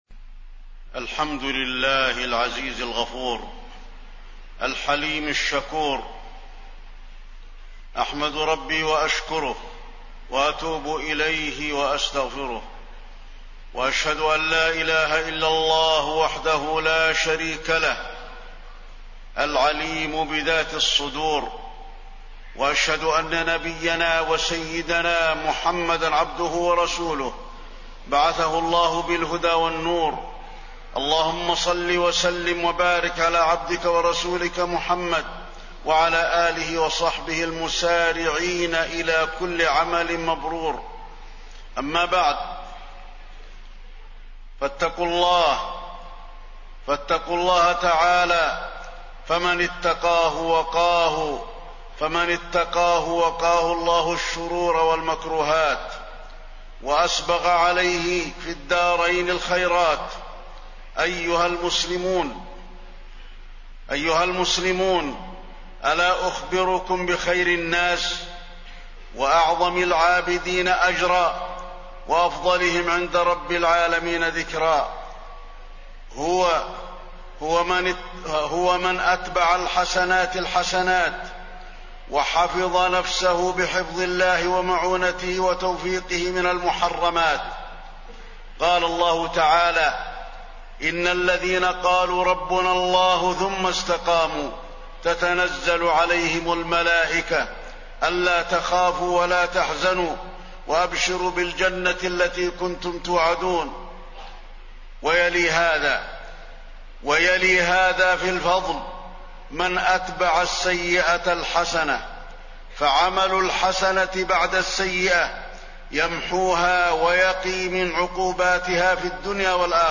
تاريخ النشر ٨ شوال ١٤٣١ هـ المكان: المسجد النبوي الشيخ: فضيلة الشيخ د. علي بن عبدالرحمن الحذيفي فضيلة الشيخ د. علي بن عبدالرحمن الحذيفي الاستقامة بعد رمضان